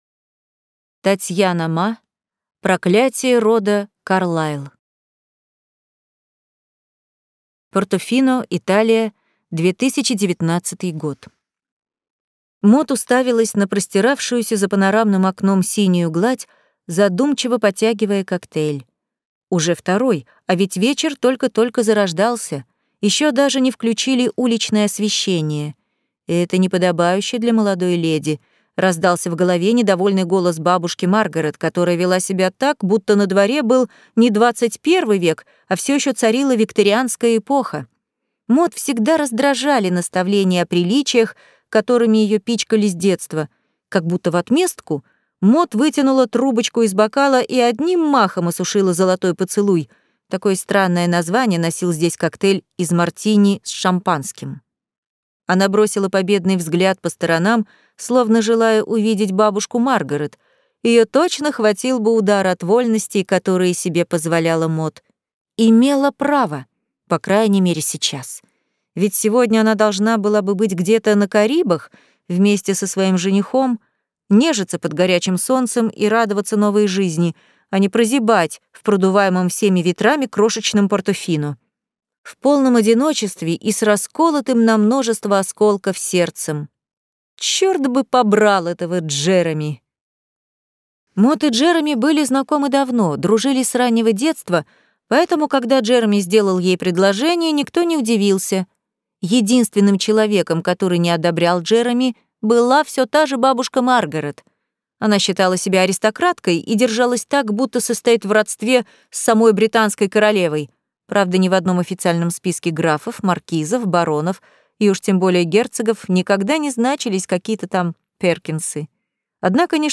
Аудиокнига Проклятие рода Карлайл | Библиотека аудиокниг